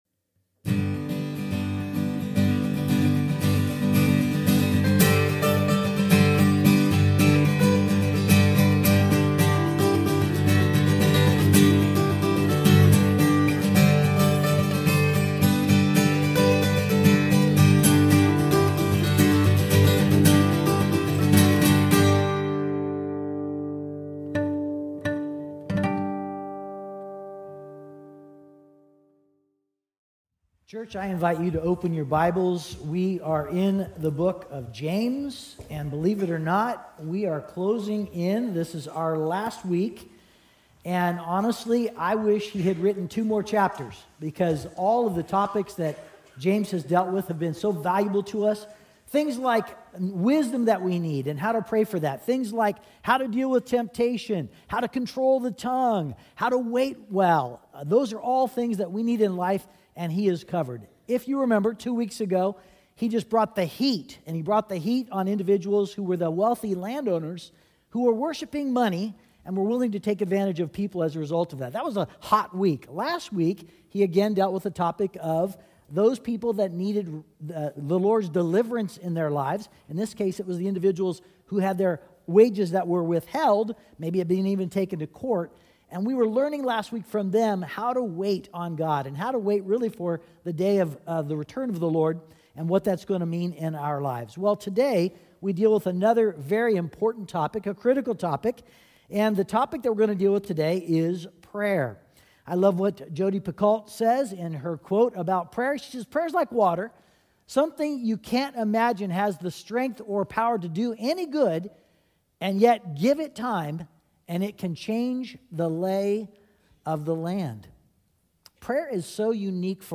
Sermon Podcast from Community Christian Fellowship in Edmonds, WA.